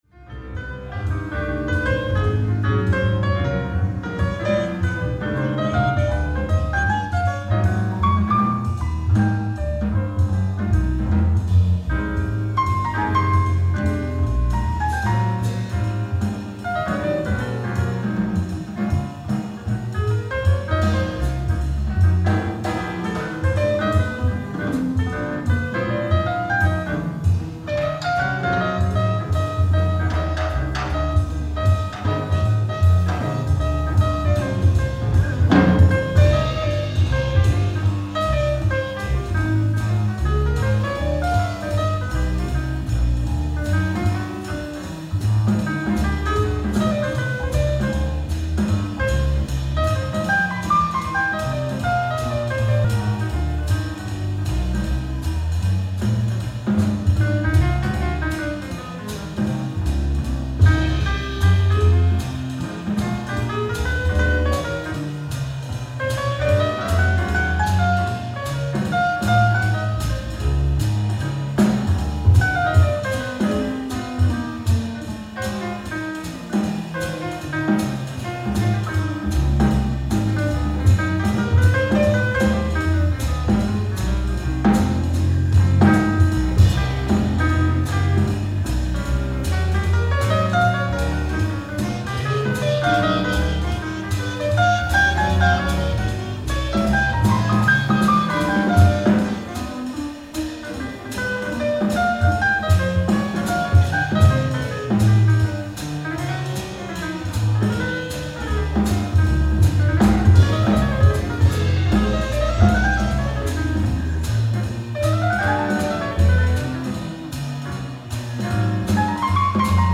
ライブ・アット・ボルドー、フランス 11/13/1990
※試聴用に実際より音質を落としています。